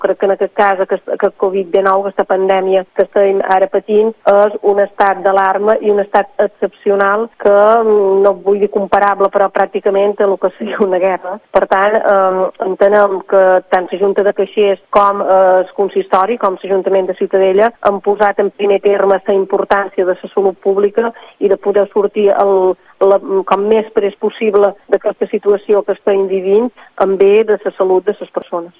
La alcaldesa de Ciutadella, Joana Gomila, ha asegurado que la decisión de cancelar las fiestas de Sant Joan en Ciutadella este año ha sido dolorosa, pero la salud es lo primero.
Alcaldesa Ciutadella